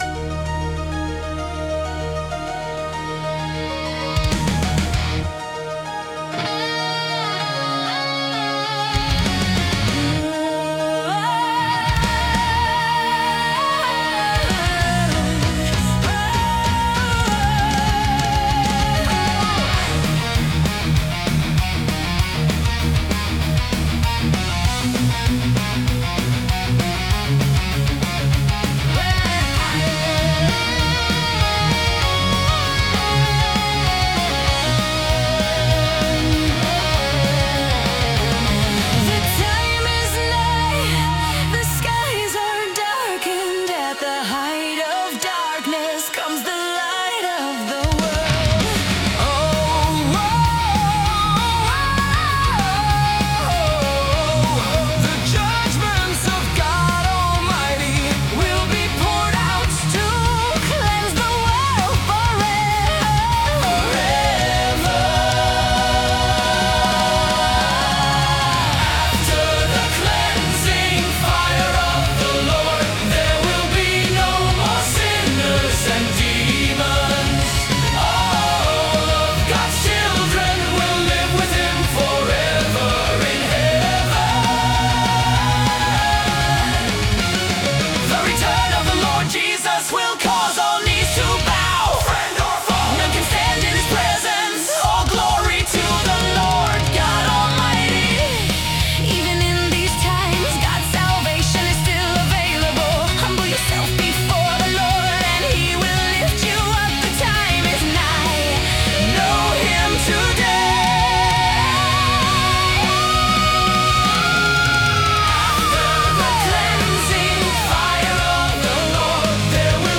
Took me a couple days to make this, I used Suno, I wrote all the lyrics and made it sound like the way I want.